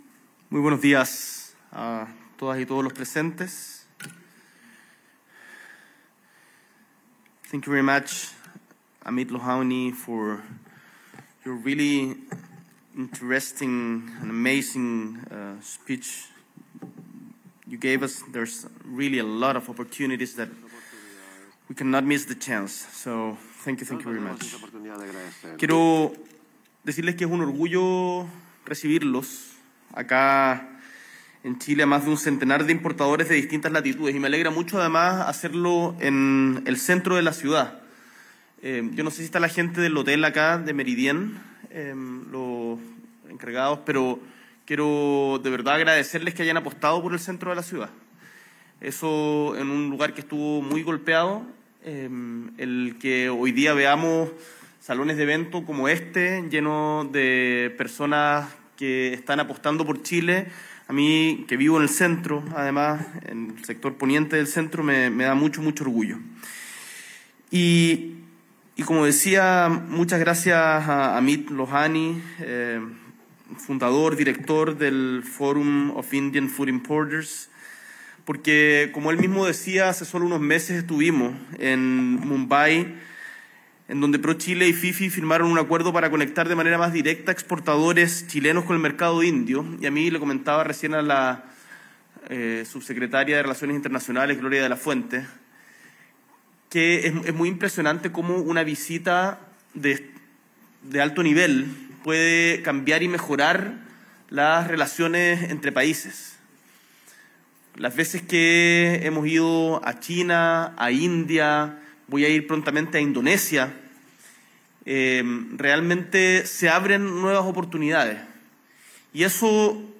S.E. el Presidente de la República, Gabriel Boric Font, encabeza la ceremonia inaugural de ENEXPRO 2025 “Diversifica tu mercado”